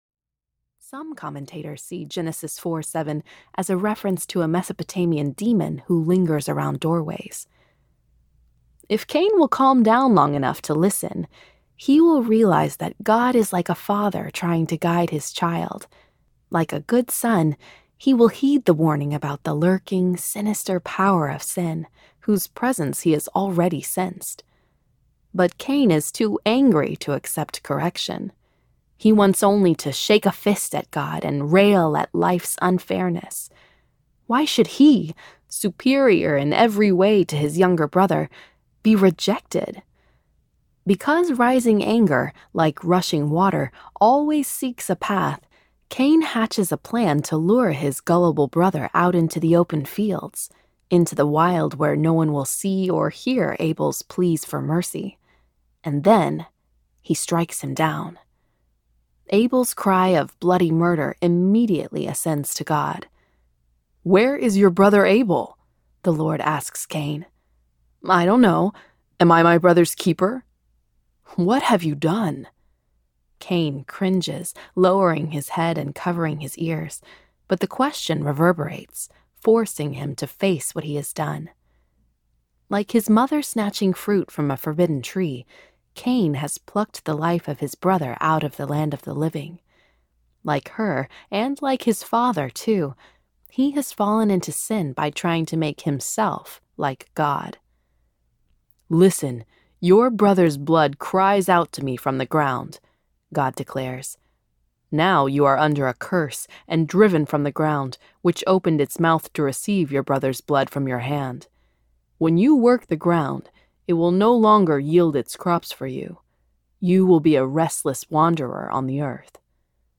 Less Than Perfect Audiobook
8.89 Hrs. – Unabridged